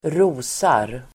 Uttal: [²r'o:sar]